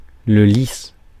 Ääntäminen
Vaihtoehtoiset kirjoitusmuodot lis Ääntäminen France: IPA: [lis] Haettu sana löytyi näillä lähdekielillä: ranska Käännös Substantiivit 1. liilia Suku: m .